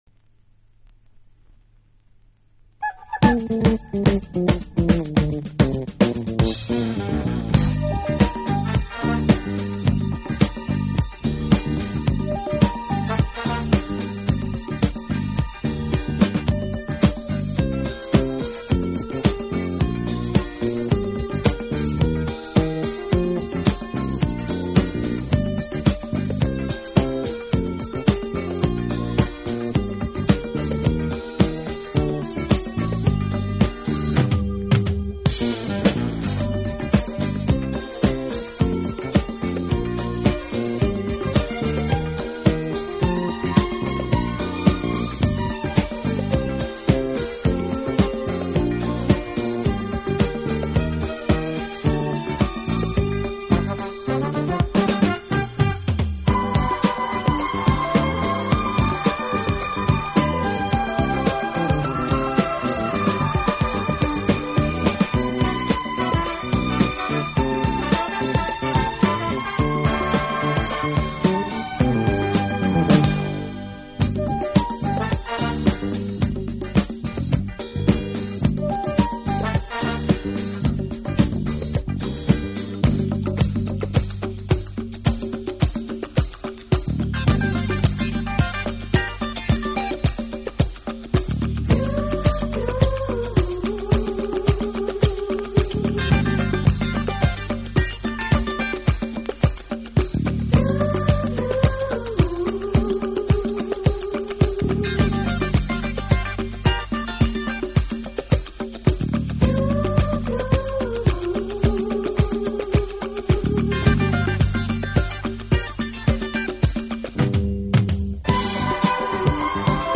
【顶级轻音乐】
他以清新、明快的音乐